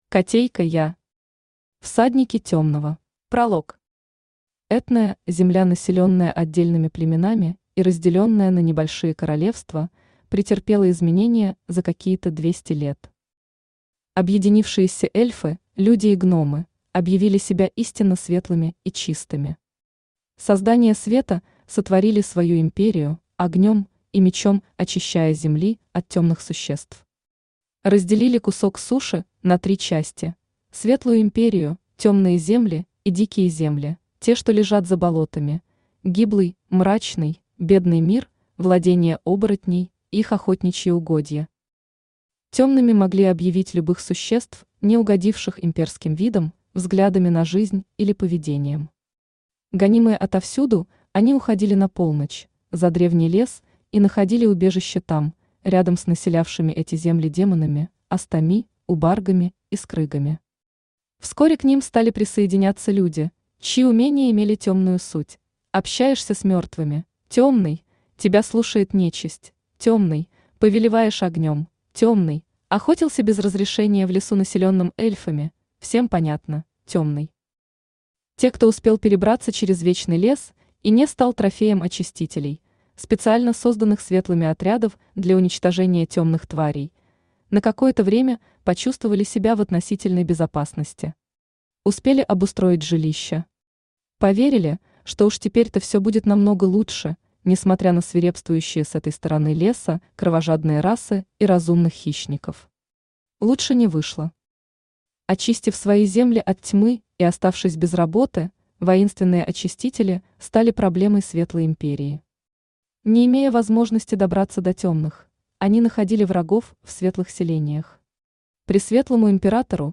Аудиокнига Всадники Темного | Библиотека аудиокниг
Читает аудиокнигу Авточтец ЛитРес.